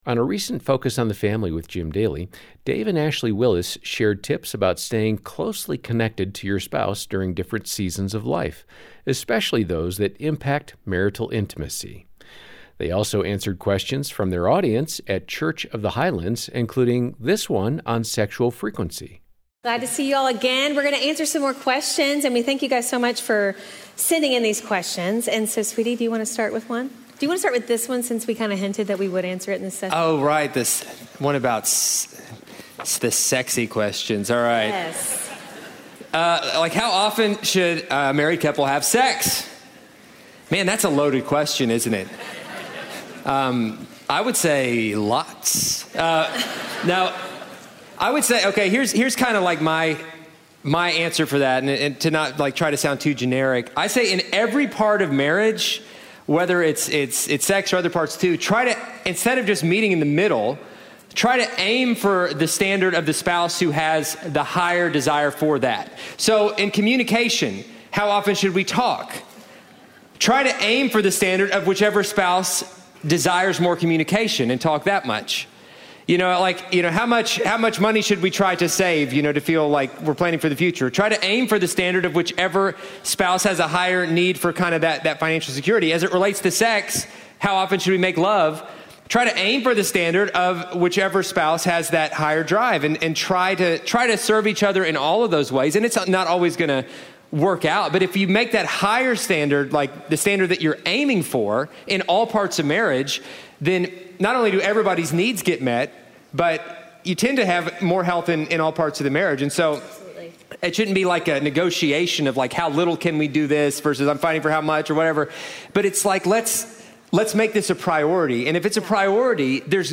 Q&A on Sexual Frequency